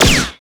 POWGSHOT02.wav